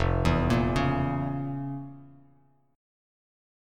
FM7sus4#5 chord